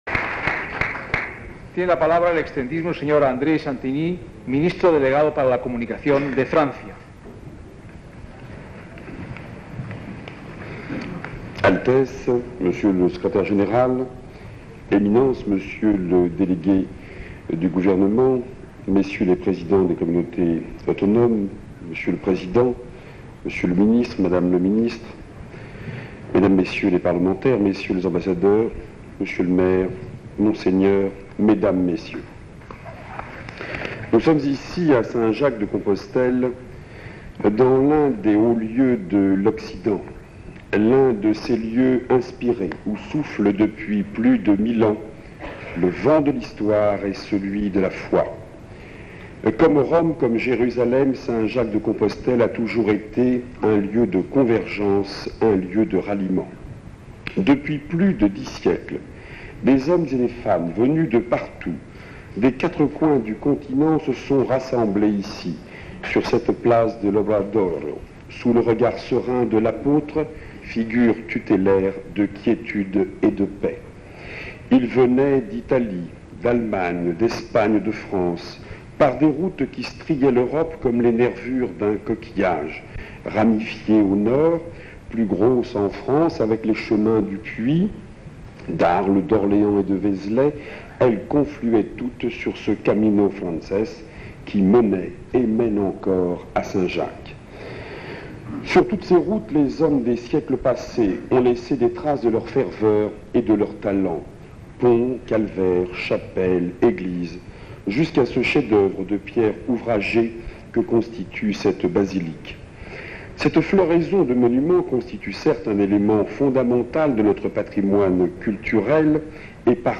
Discurso de D. André Santini, Ministro delegado de Comunicación de Francia
Acto de proclamación del Camino de Santiago como Itinerario Cultural Europeo. 1987